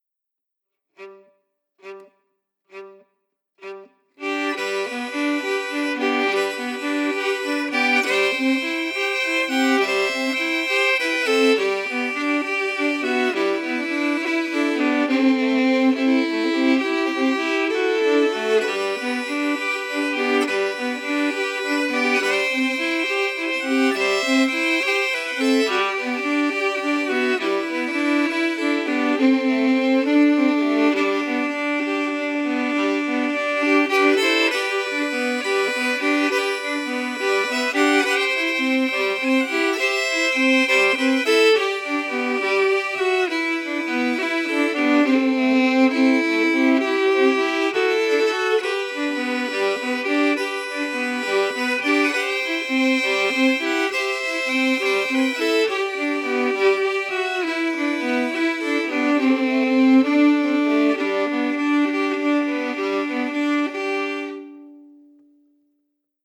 Key: G
Form: Jig
Harmony Emphasis
Source: Trad.
Region: Ireland